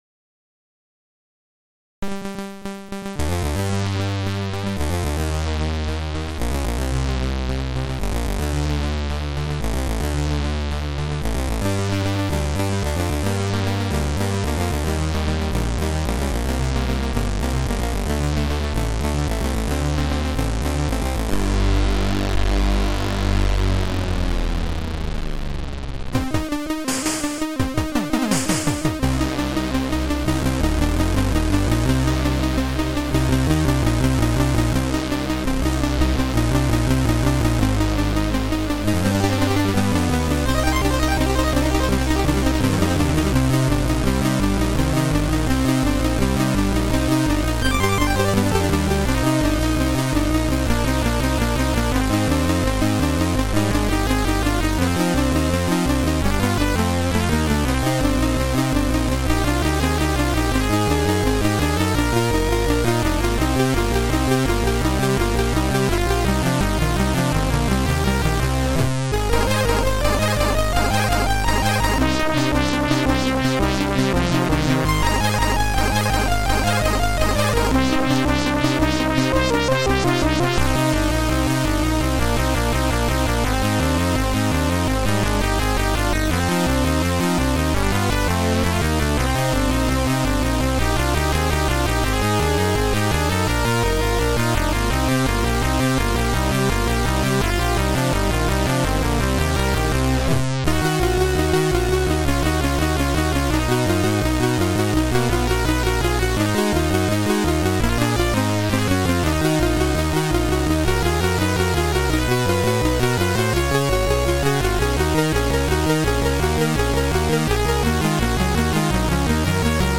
Sound Format: C64 SID